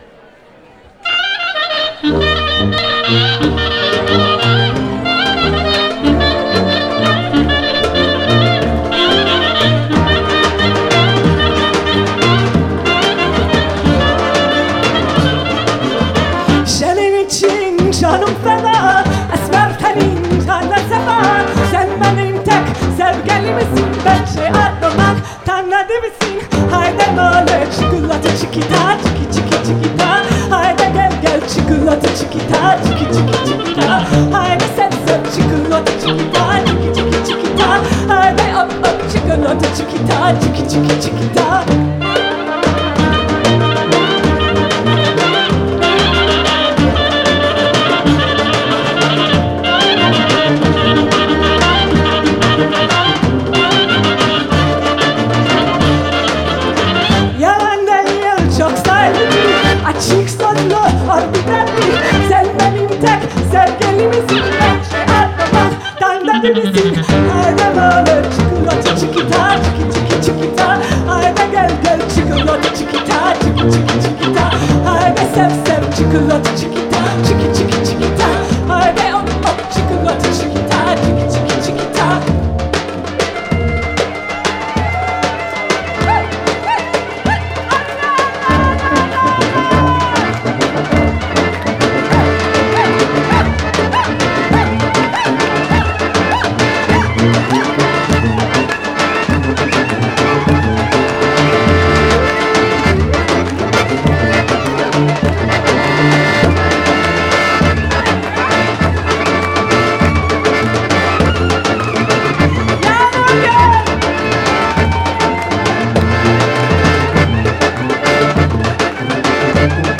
Haydi Sev Sev, aka Chikolata (D min) - VOCAL
Gig